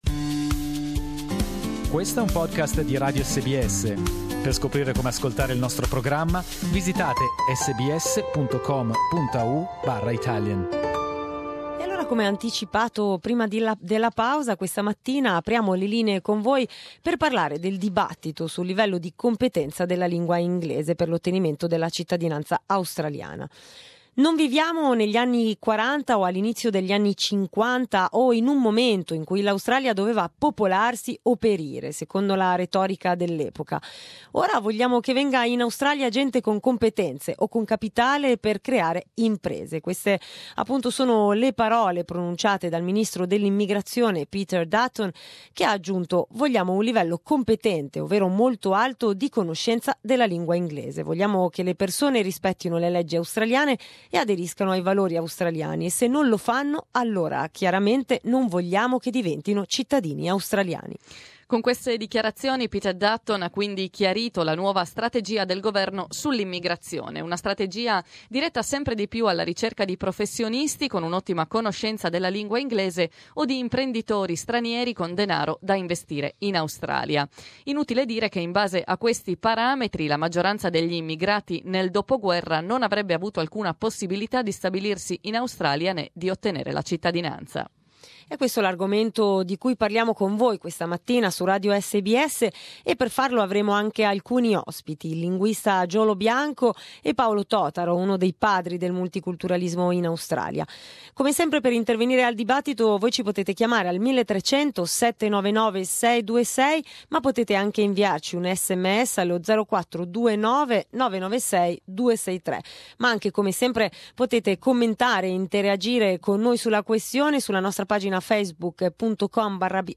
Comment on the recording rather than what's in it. Open lines this morning to talk about the debate on the level of English language competence required to migrate down under.